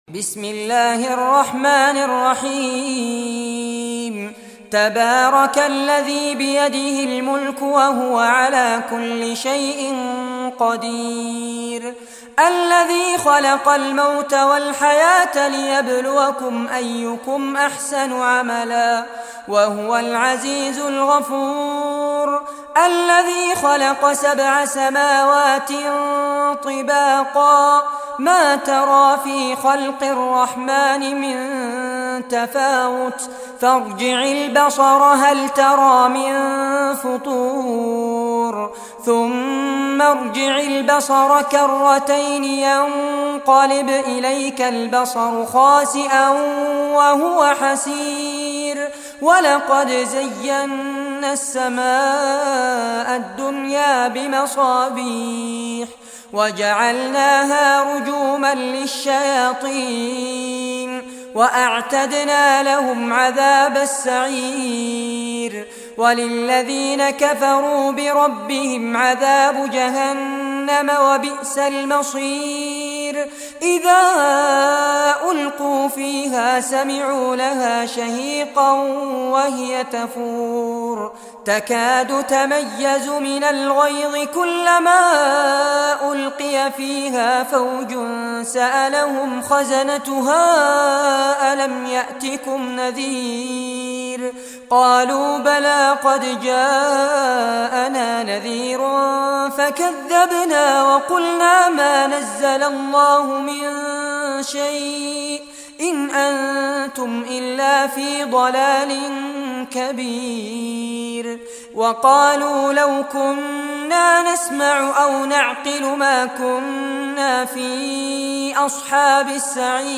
Surah Mulk Recitation by Fares Abbad